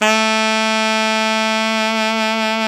SAX ALTOFF03.wav